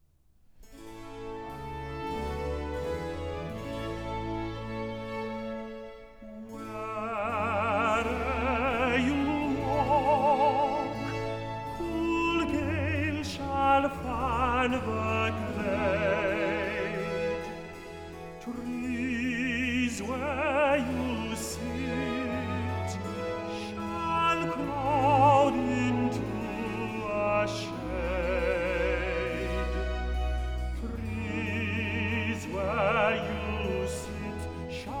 Classical Opera
Жанр: Классика